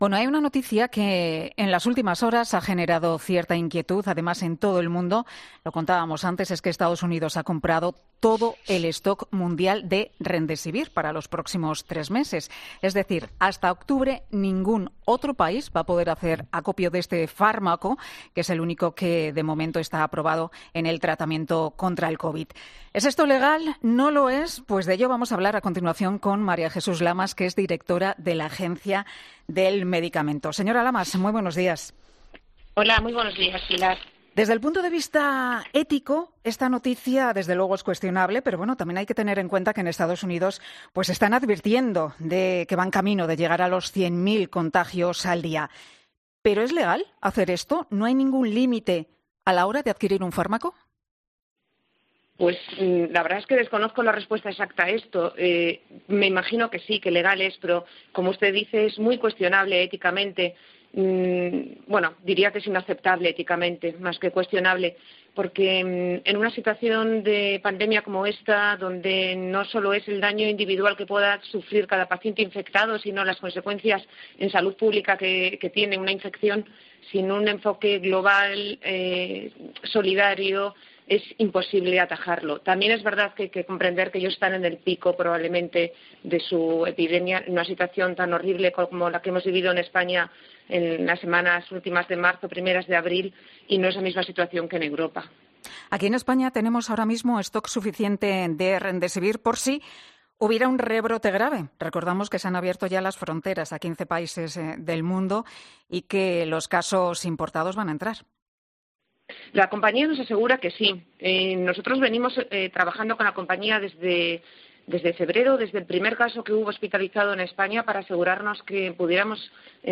La directora de la Agencia Española de Medicamentos y Productos Sanitarios María Jesús Lamas ha garantizado este jueves en una entrevista en "Herrera en COPE" que todas las pruebas cumplen con los criterios de eficacia y seguridad que se piden en condiciones normales.